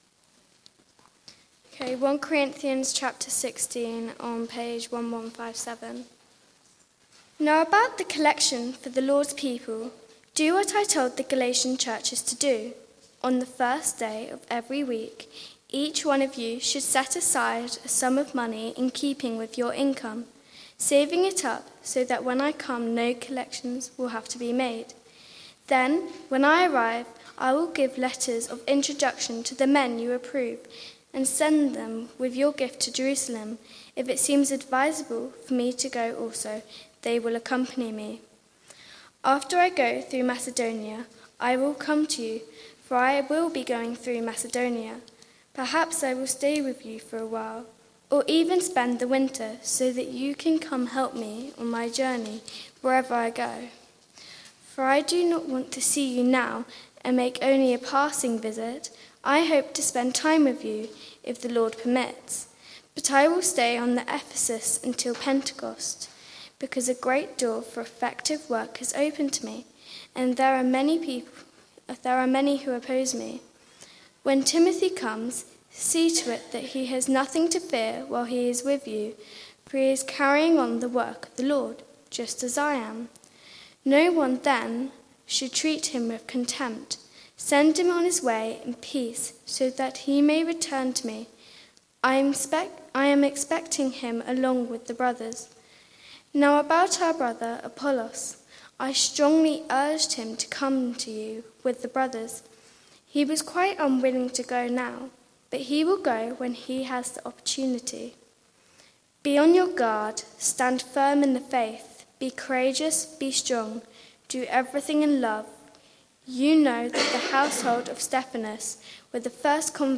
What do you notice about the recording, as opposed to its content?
Church at the Green Sunday 4pm